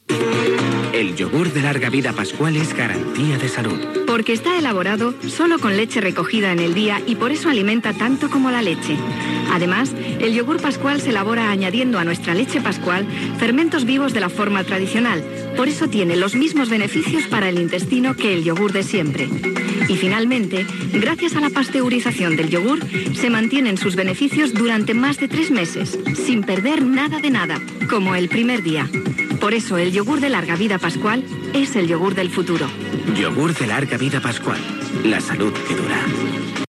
Anunci de iogurt Pascual
FM